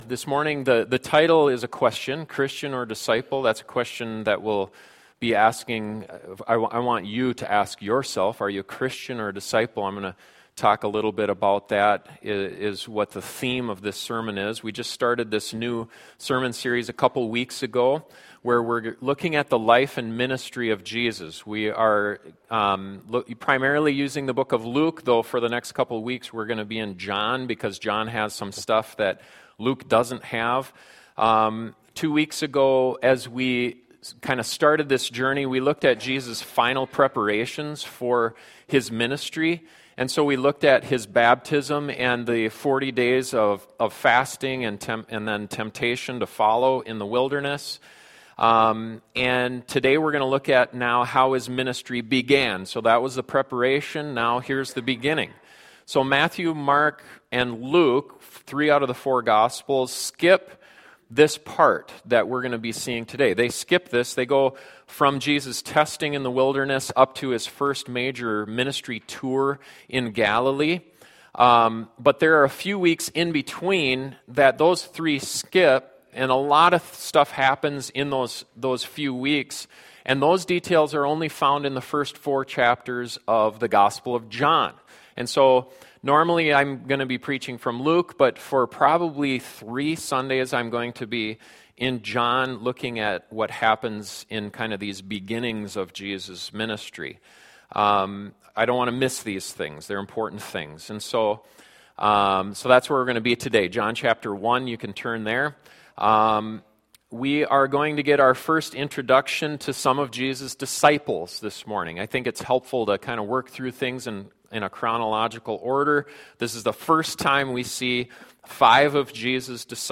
When Jesus began his ministry he gathered disciples and this sermon helps us to see what they were like and what Christ is calling us into.